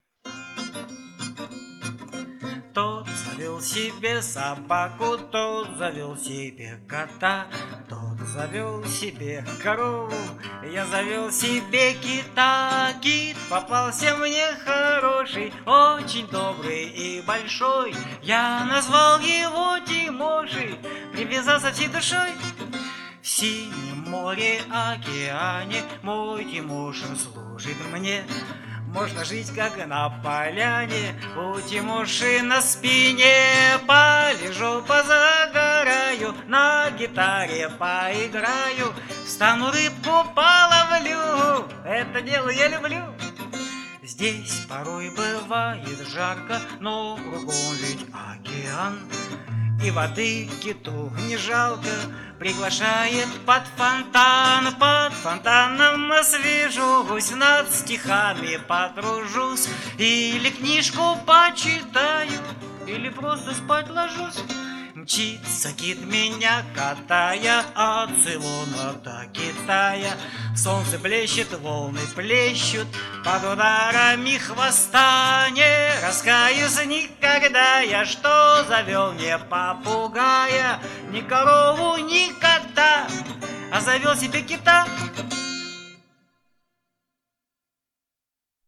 В АВТОРСКОМ ИСПОЛНЕНИИ